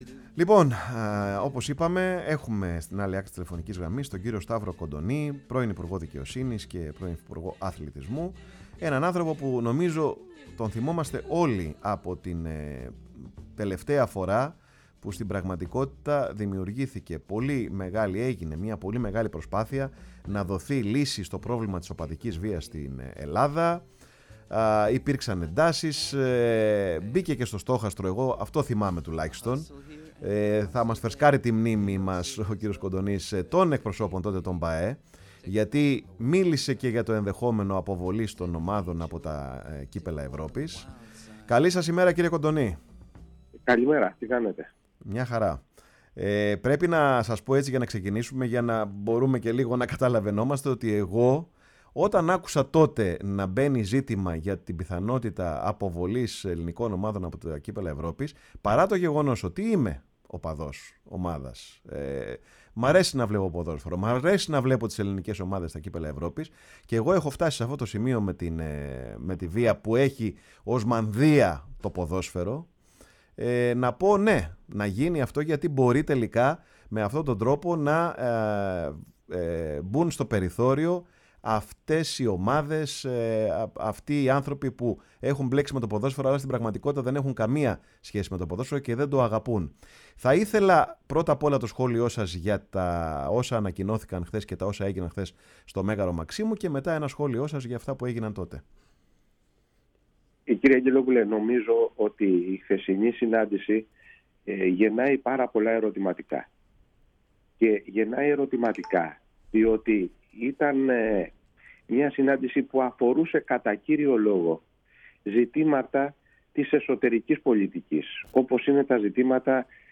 Ακούστε τη συνέντευξη του κ. Σταύρου Κοντονή στη “Φωνή της Ελλάδας”.